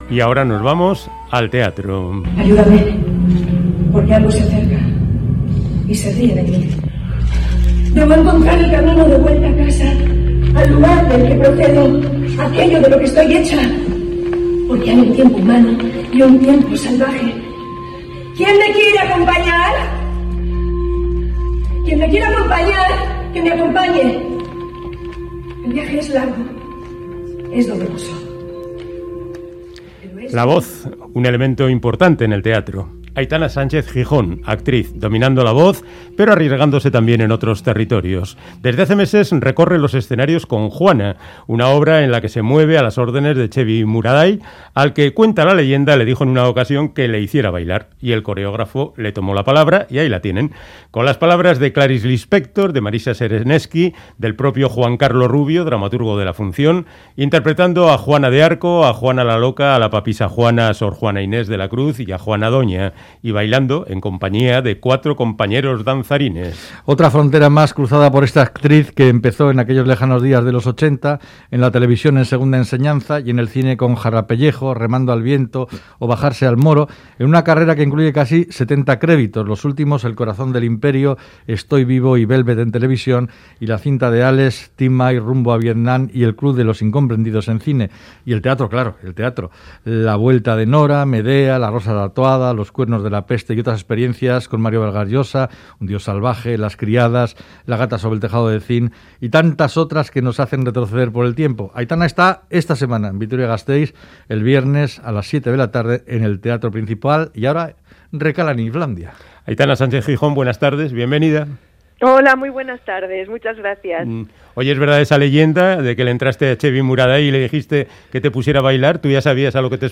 Audio: Charlamos con la actriz Aitana Sánchez-Gijón que este viernes llegará a Vitoria-Gasteiz con Juana una obra en la que además de actuar baila por primera vez